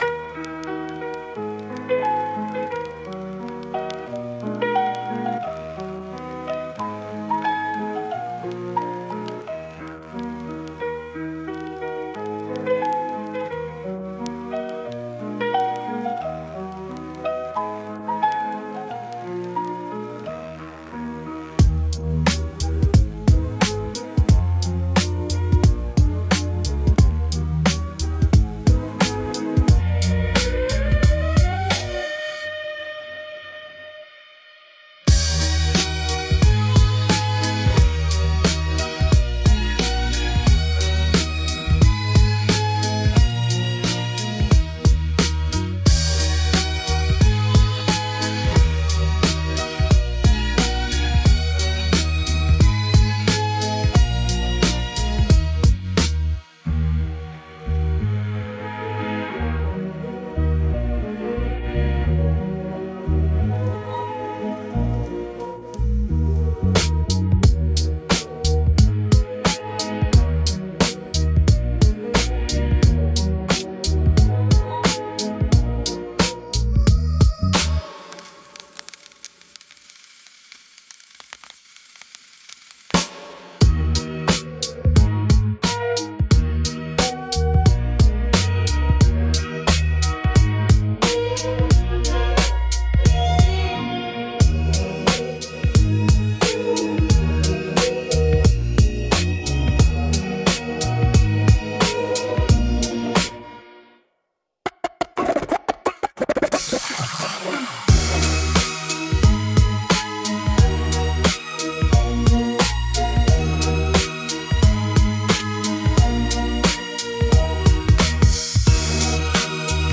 #17 — (Instrumental)